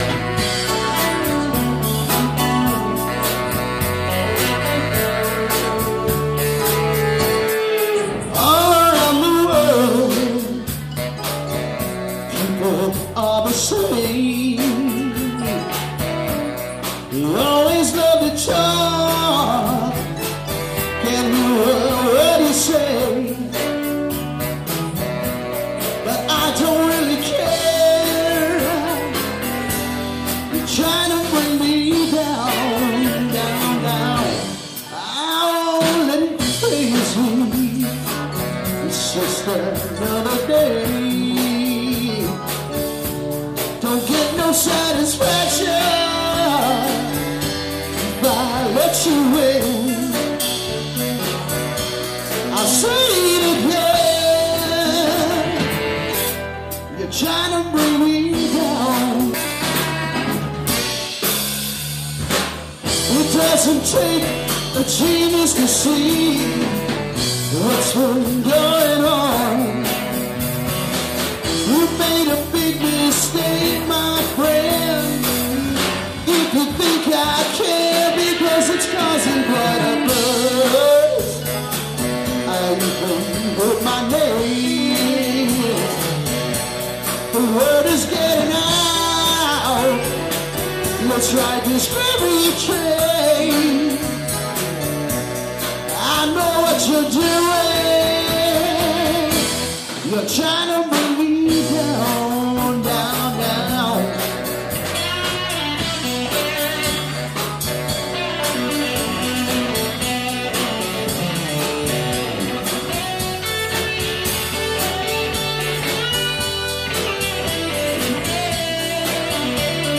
She is most recognized as a soulful, powerful lead singer, songwriter, composer, producer, arranger, keyboardist and exceptional harmonizer.